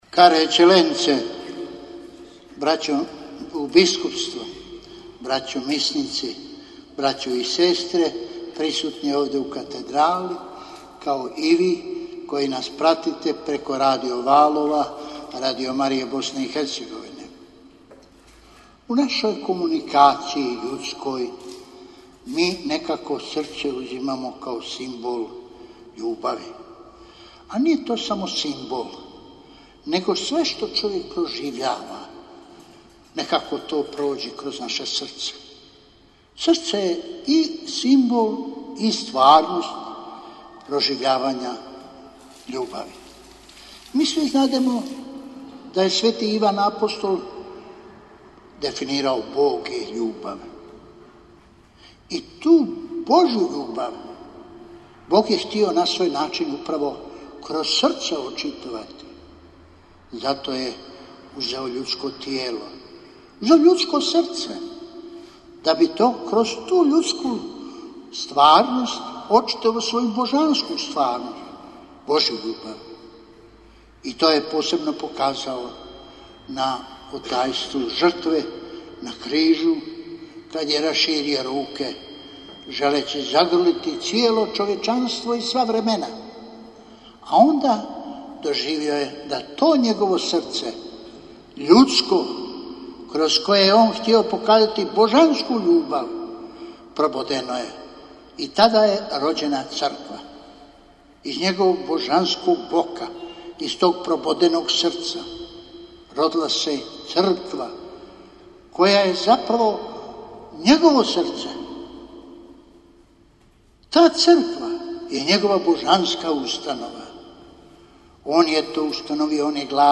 Svečanim Euharistijskim slavljem u nedjelju, 30. lipnja 2019. u katedrali Srca Isusova u Sarajevu obilježen je Papin dan i proslavljen naslovnik Vrhbosanske nadbiskupije Presveto Srce Isusovo.